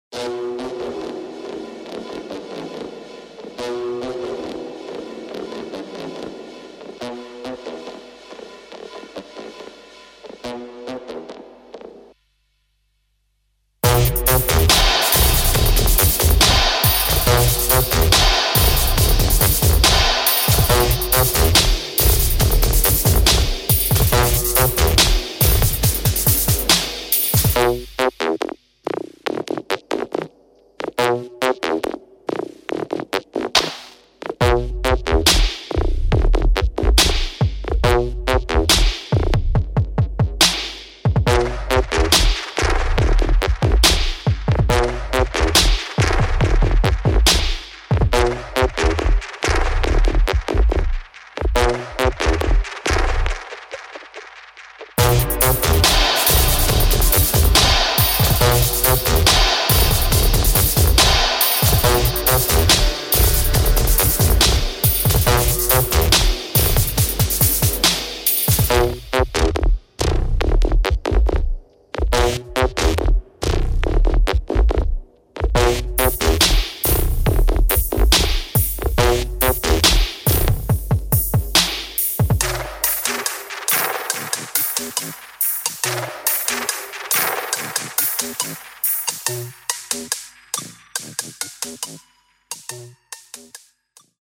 [ GRIME | DUBSTEP | BASS ]
Instrumental